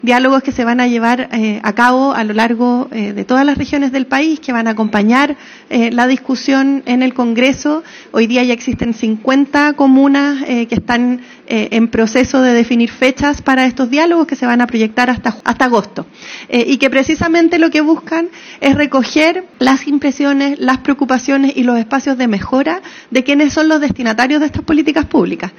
El Gobierno de Chile, a través del Ministerio Secretaría General de Gobierno y el Ministerio de Educación, inició un ciclo de Diálogos Ciudadanos titulado “Hacia un nuevo Financiamiento para la Educación Superior (FES)” en la Biblioteca Municipal de Pudahuel.